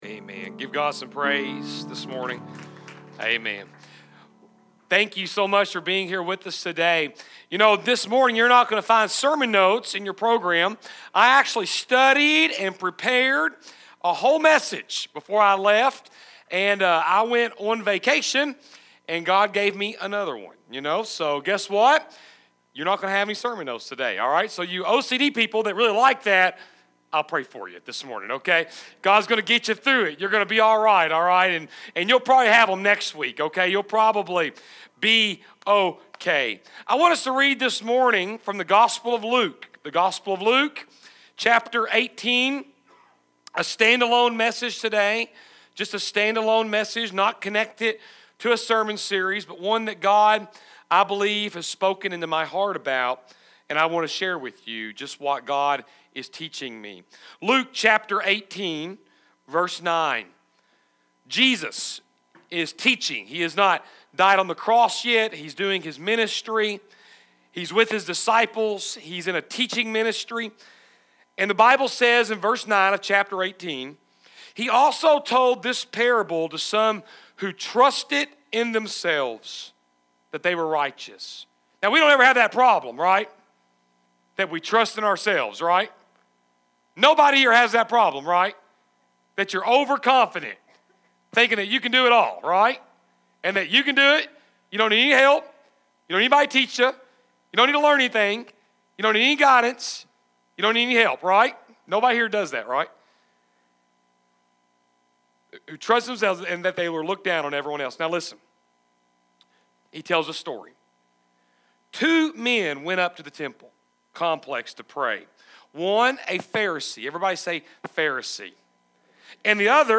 Sermon focusing on the parable of the Pharisee and Tax Collector.